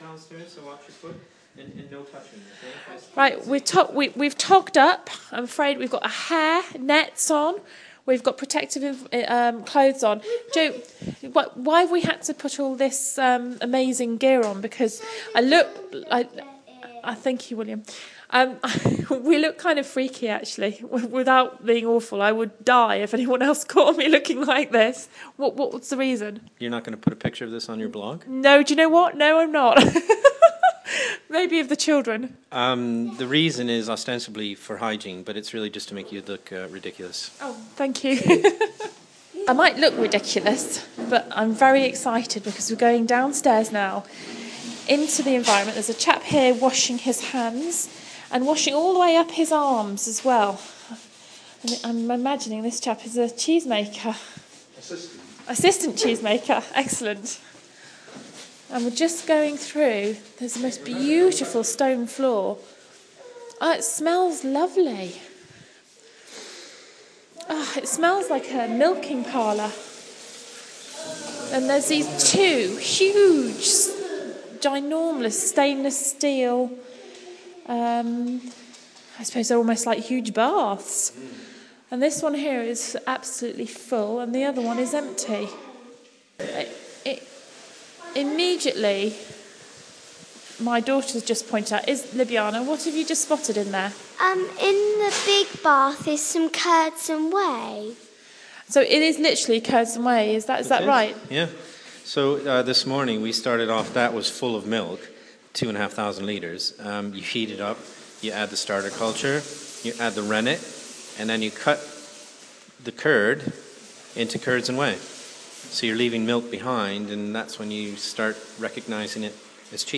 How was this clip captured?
being shown round Stichelton dairy